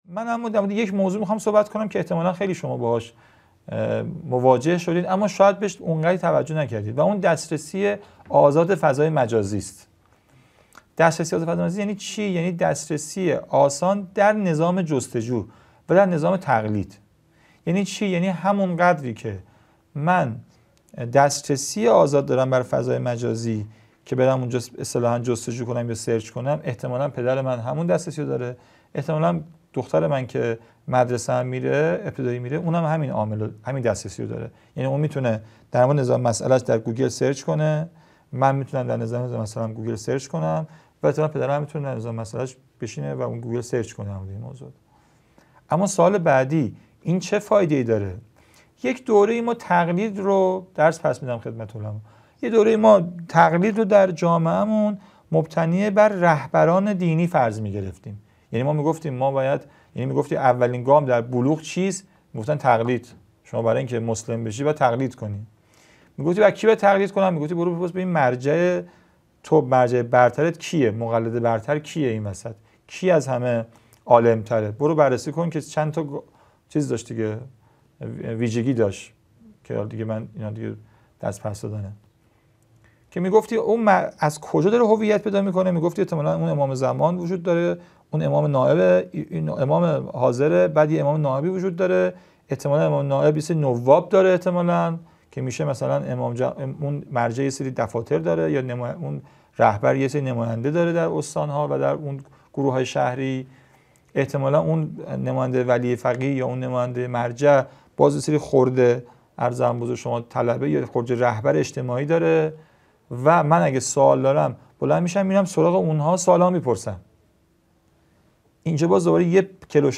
مدرسه عالی هیأت | گزیده هفتم از دومین سلسله نشست‌ های هیأت و نوجوانان - با موضوعیت نوجوان در ایران
قم - اردبیهشت ماه 1402